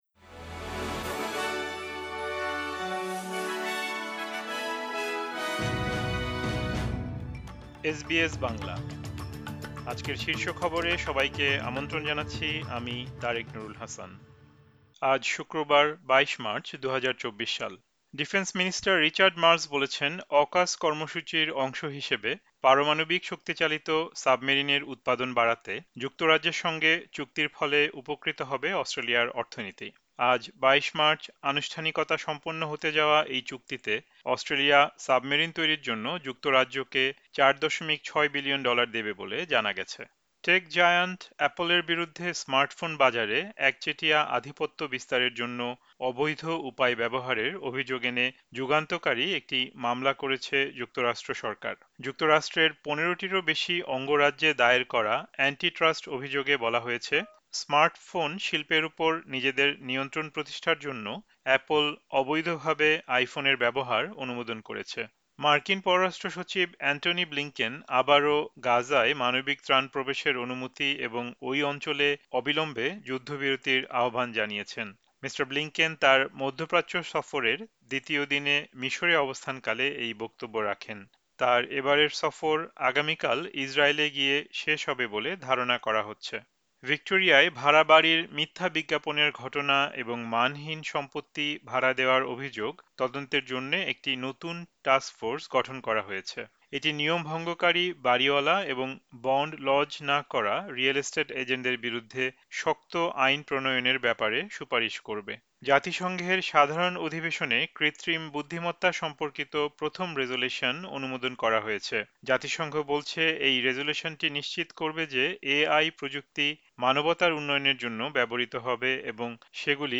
এসবিএস বাংলা শীর্ষ খবর: ২২ মার্চ, ২০২৪